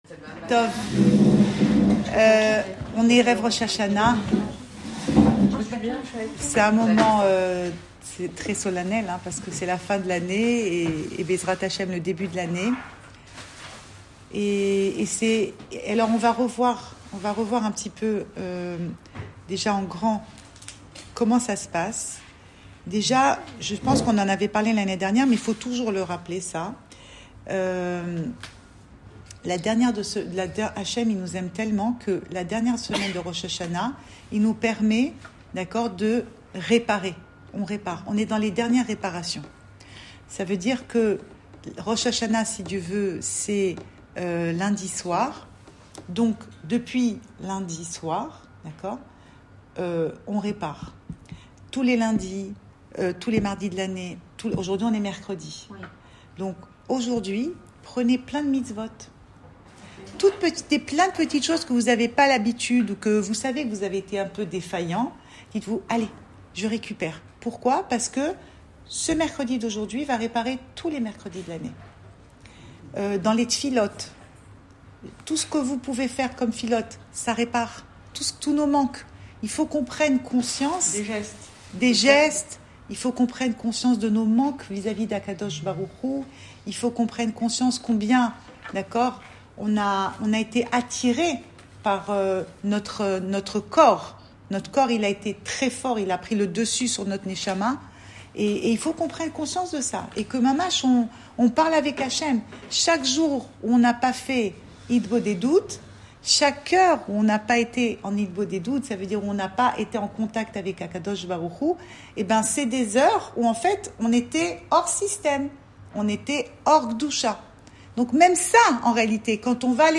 Cours audio Fêtes Le coin des femmes Pensée Breslev - 1 septembre 2021 2 septembre 2021 Préparation à Roch Hachana à Tel Aviv. Enregistré à Tel Aviv